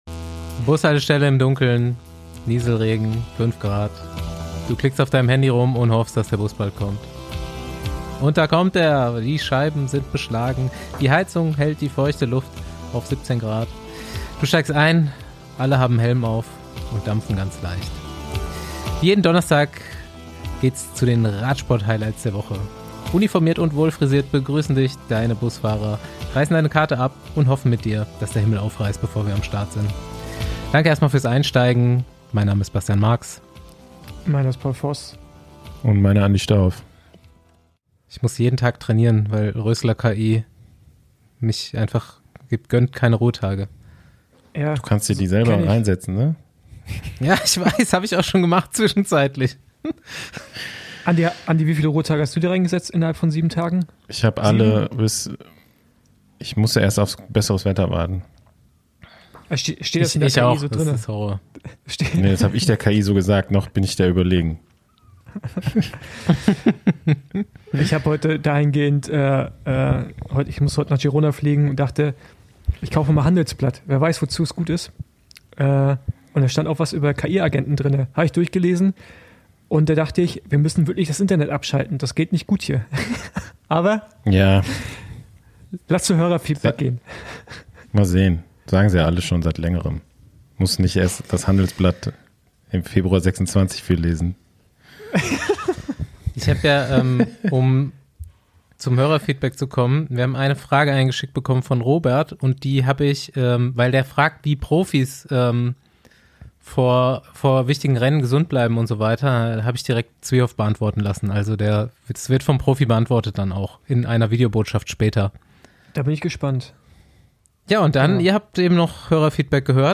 Februar '26 Der Besenwagen hält an altbekannter Adresse in Bregenz am Bodensee. Nach fast 8 Jahren ist Pascal Ackermann wieder zu Gast auf dem Beifahrersitz. Eine Handvoll Grand Tour-Etappen und viele weitere Siege sind in der Zwischenzeit auf die Palmarès gekommen. 2026 geht er nach zwei Zwischenstationen mit vielen Rückschlägen für das Team Jayco-AlUla als Sprintkapitän an den Start und hat wieder Etappensiege im Visier.
Die Intervall-Musik blieb jedenfalls unverändert!